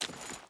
Footsteps / Water / Water Chain Run 4.wav
Water Chain Run 4.wav